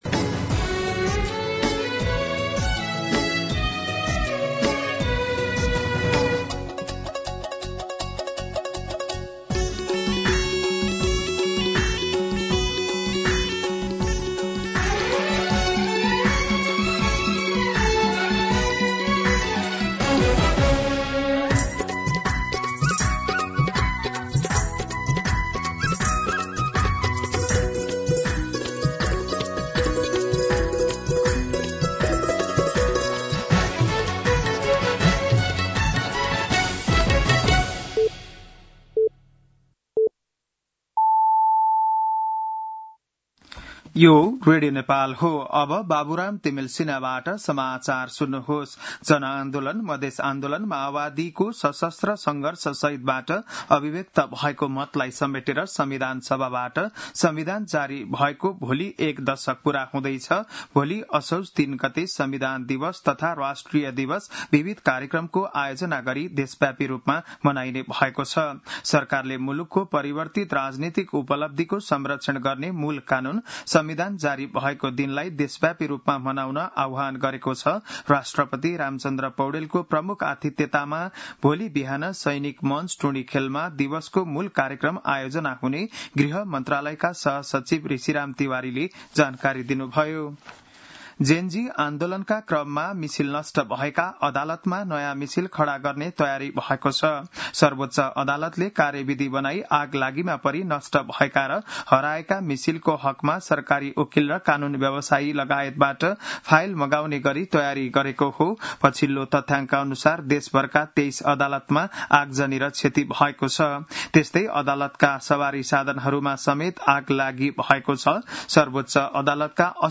An online outlet of Nepal's national radio broadcaster
बिहान ११ बजेको नेपाली समाचार : २ असोज , २०८२